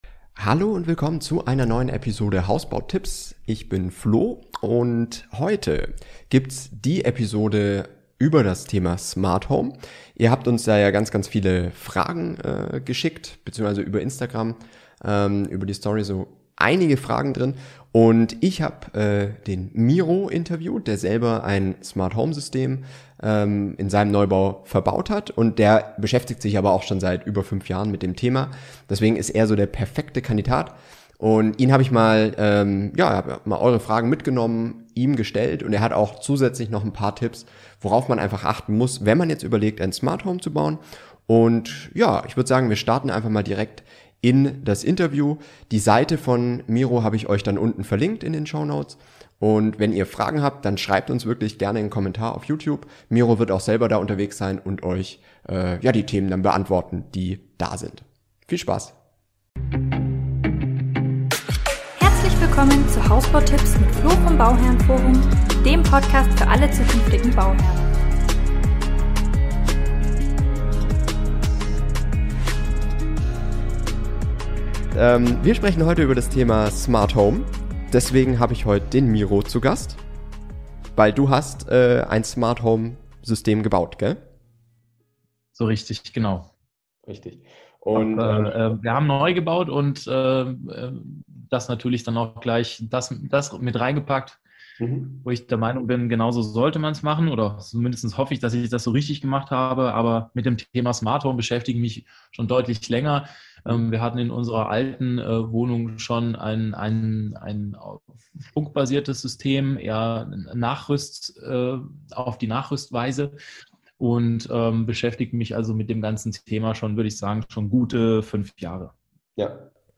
Smart Home - Spielerei oder Zukunft? Interview